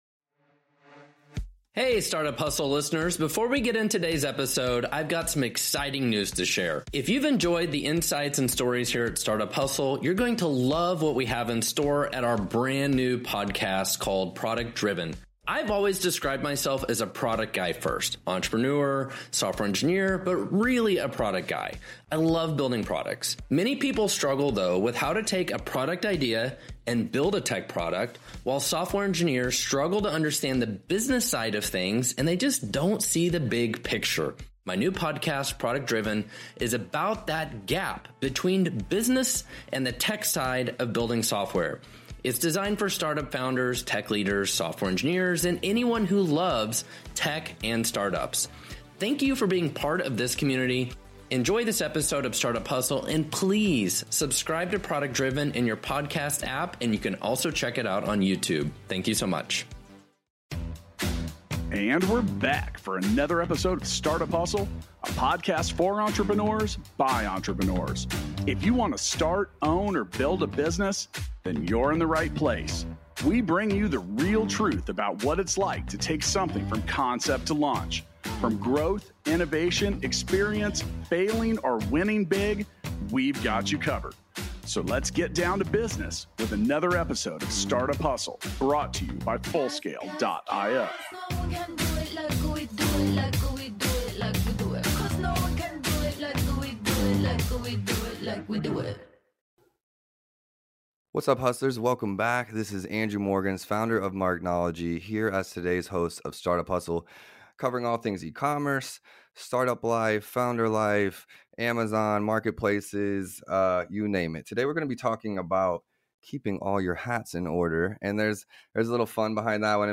for a fun conversation about keeping all your various responsibilities in order. Listen in for insights on how to overcome challenges, find a perfect partner, and thrive through company shifts.